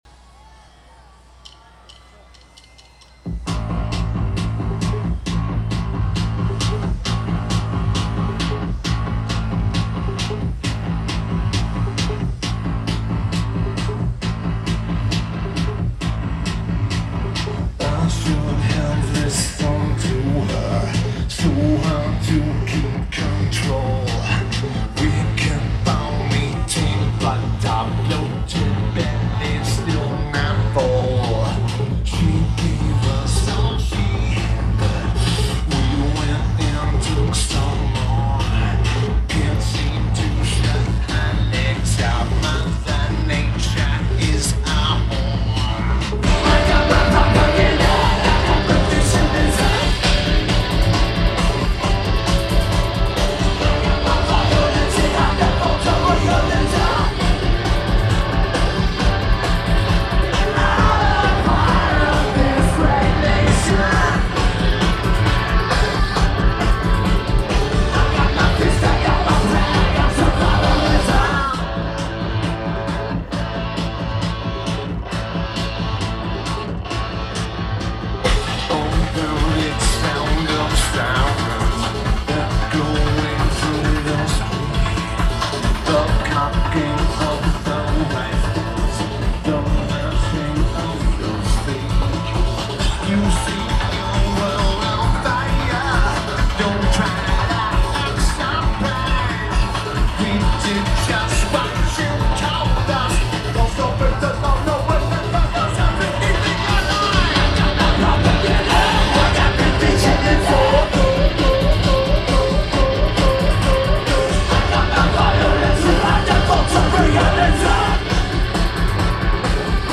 Louder Than Life Festival
Lineage: Audio - AUD (Zoom H2)